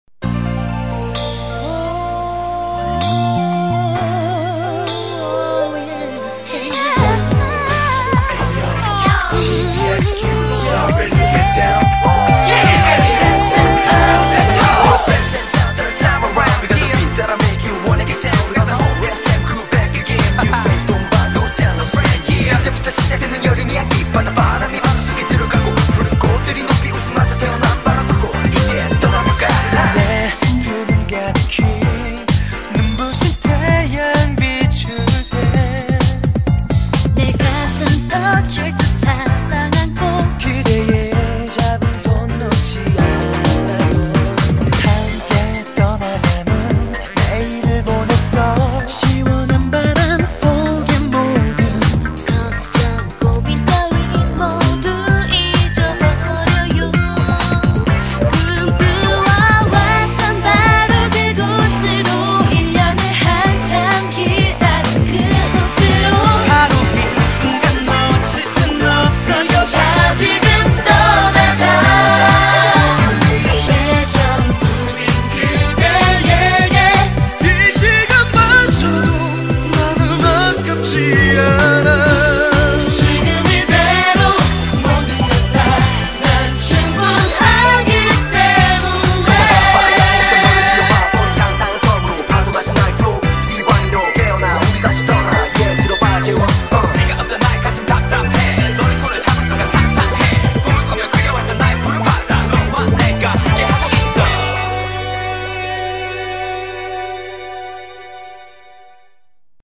BPM129--1
Audio QualityPerfect (High Quality)